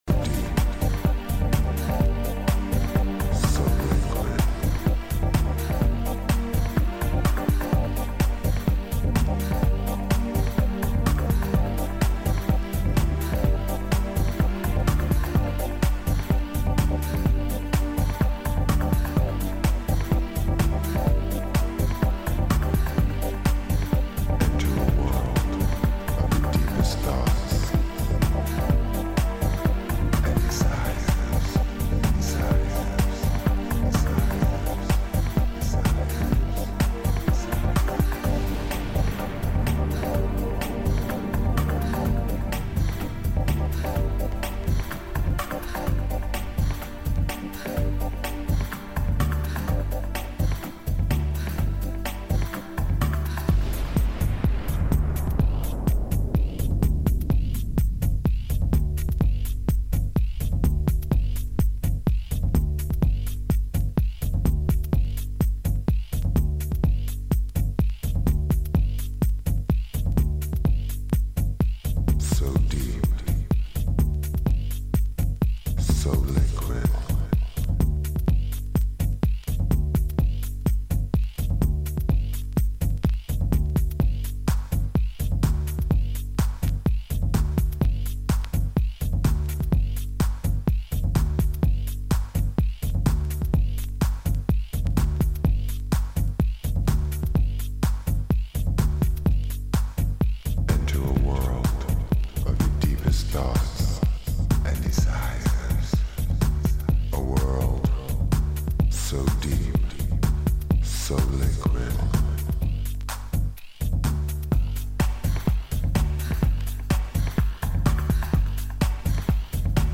'94年オランダ産ディープ・ハウス超名作シングル！！